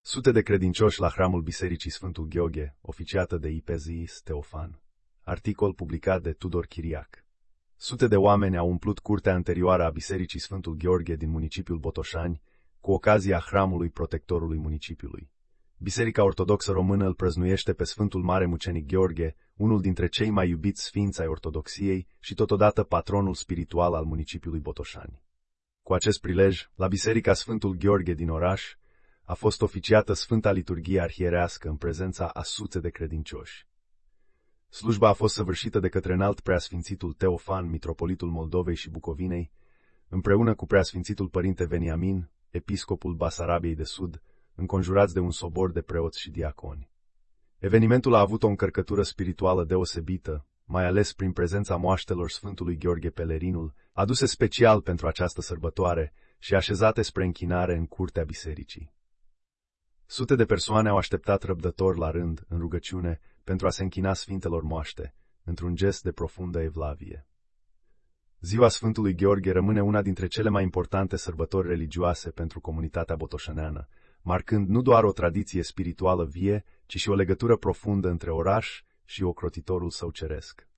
Știri Audio
Sute de credincioşi la hramul Bisericii Sfântul Gheoghe, oficiată de IPS Teofan (video)